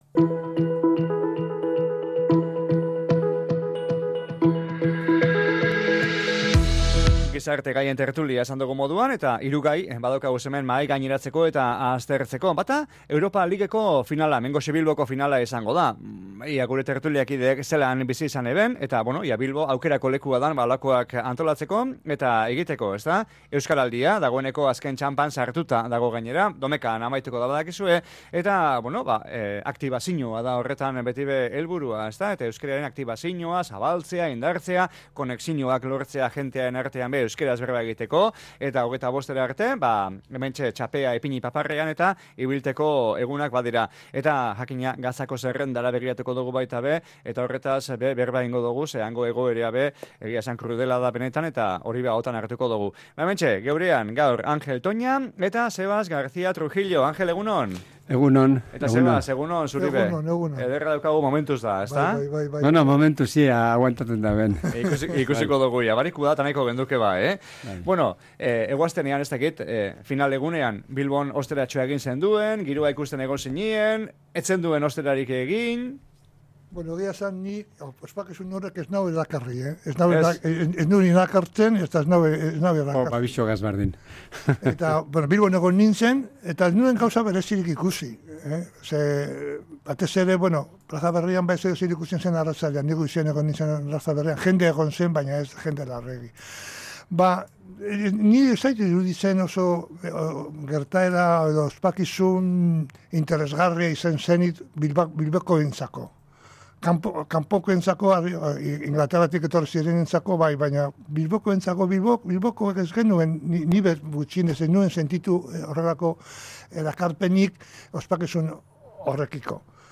Asteko gai batzuen errepasoa gizarte gaien tertulian
GIZARTE-GAIEN-TERTULIA.mp3